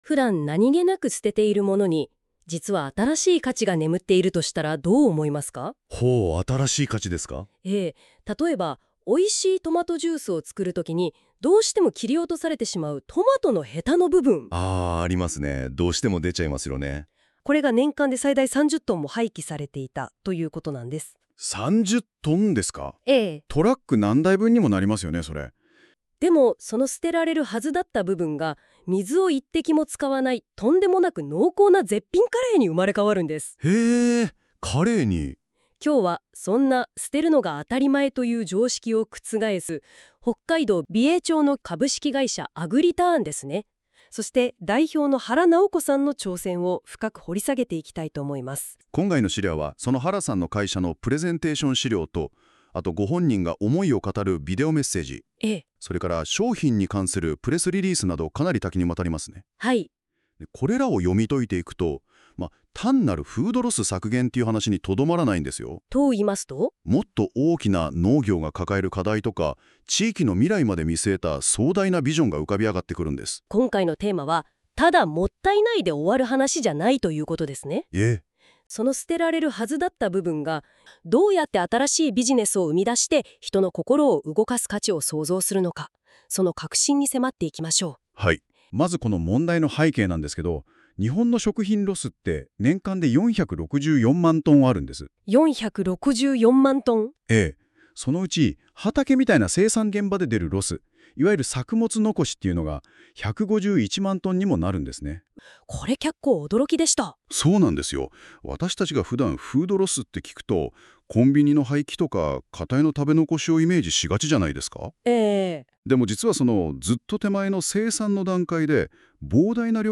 私の挑戦をラジオ感覚で聴けます！